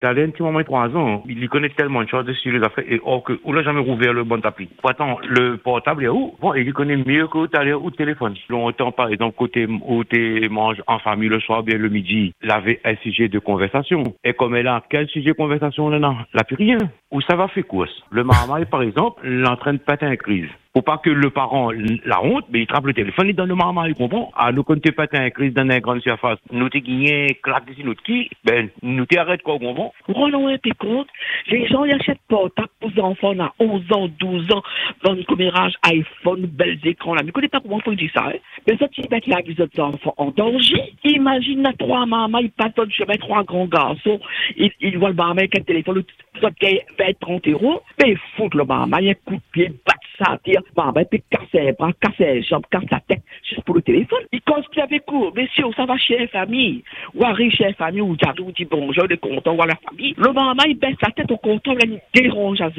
Sur notre antenne, vous avez été nombreux à réagir à ce sujet sensible qui oppose générations et modes de vie.